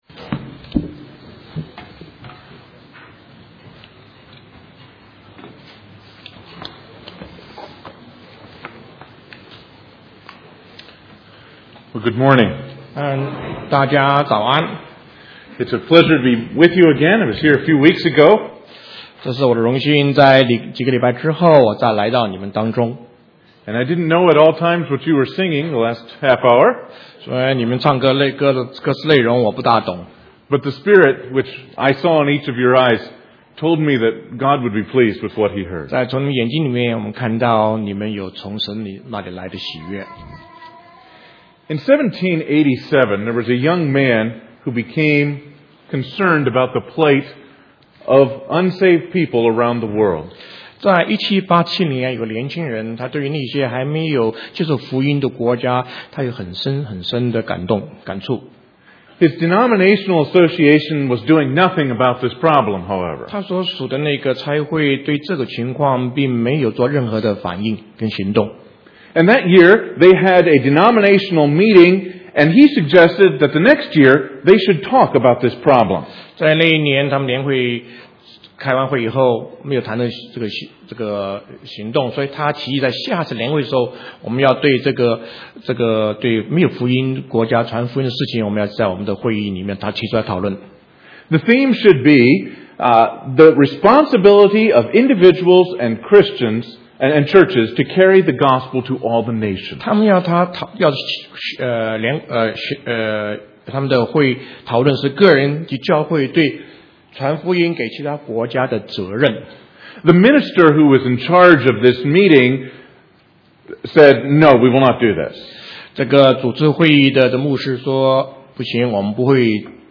English Sermons